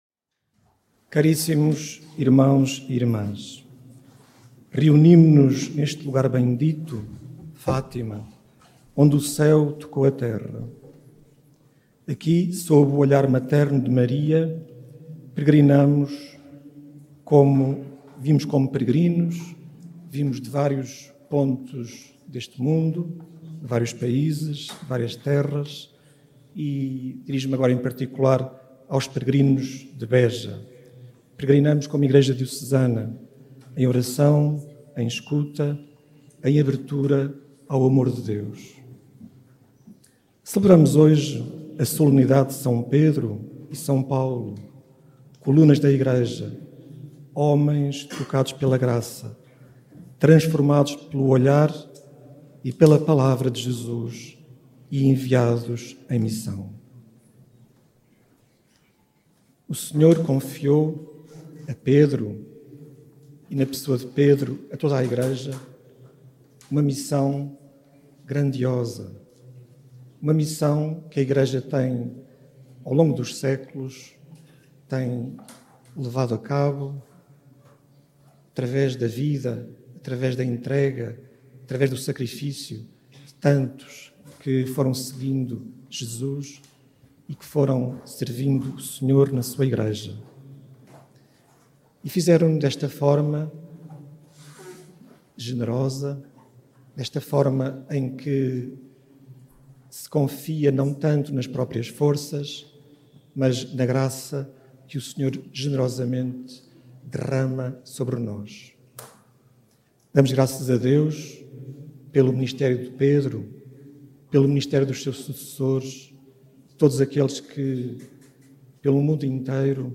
Os peregrinos reunidos em assembleia eucarística, na manhã deste domingo, no Recinto de Oração do Santuário de Fátima, foram exortados a deixarem-se olhar, amar e transformar por Jesus e a viver com generosidade e em comunhão com o Papa a missão de uma Igreja em saída, missionária e próxima.
Áudio da homilia de D. Fernando de Paiva